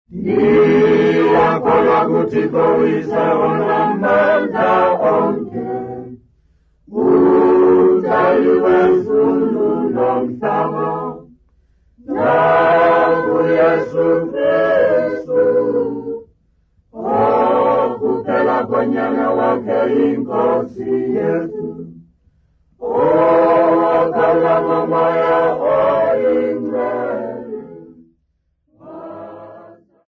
Church music South Africa
Hymns, Xhosa South Africa
Folk music South Africa
Africa South Africa Lumko, Eastern Cape sa
field recordings
An unaccompanied traditional hymn